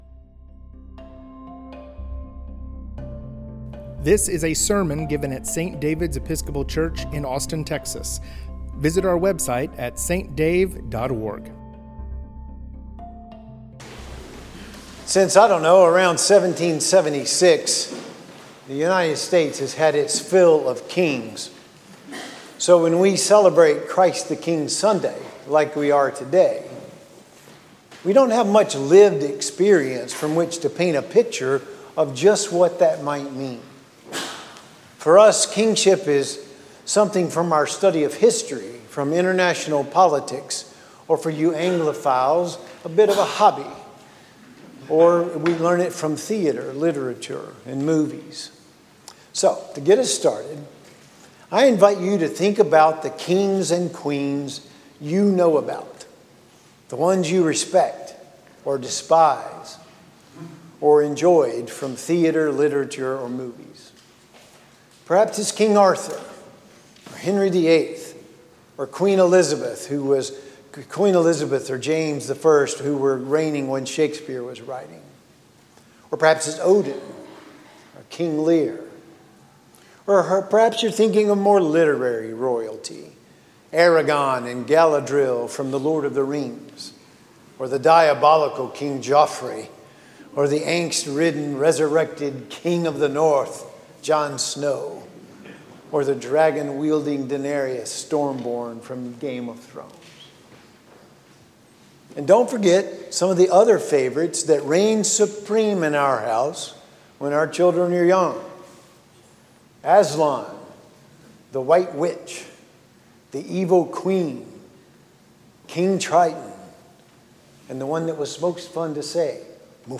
sermon from the Last Sunday after Pentecost: Christ the King